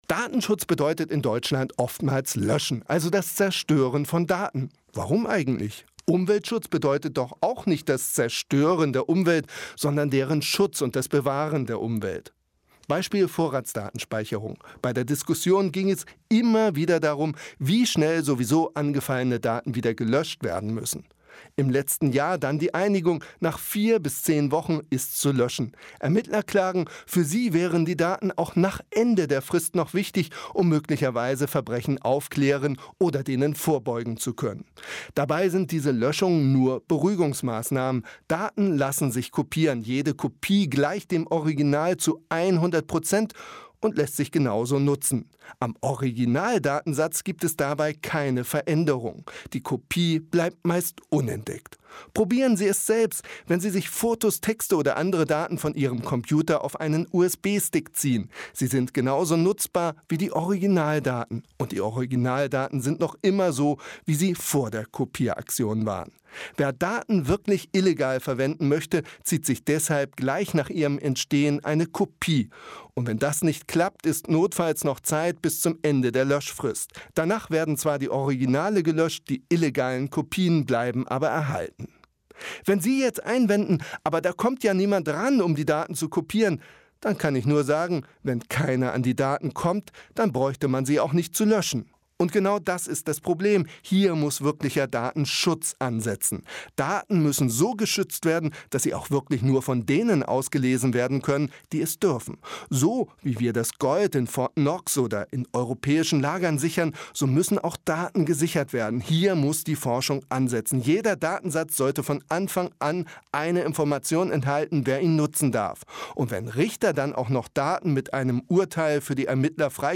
Kommentar: Warum heißt DatenSCHUTZ fast immer DatenLÖSCHUNG?
Bei MDR INFO fordere ich in einem Kommentar einen dritten Weg.